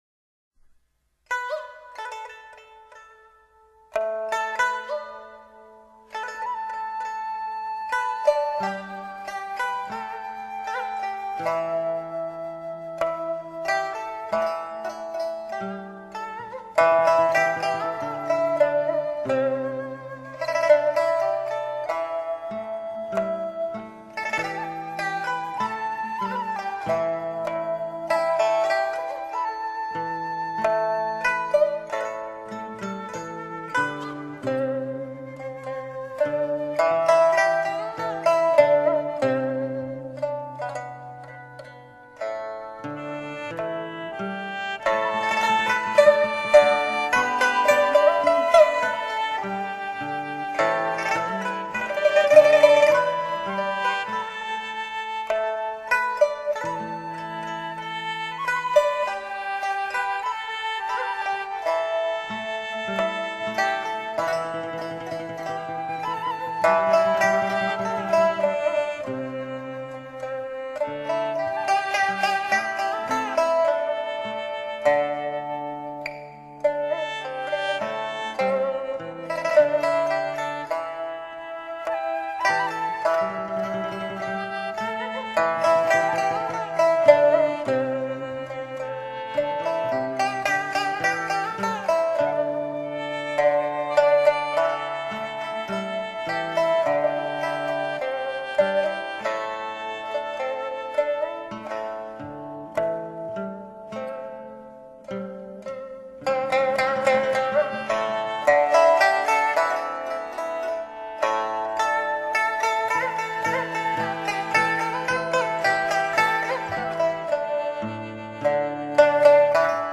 发音优美，音质纯净圆润，轮指清晰饱满，富有穿透力，弹挑铿锵有力，擅长对乐曲作细致刻画。